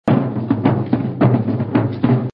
drums4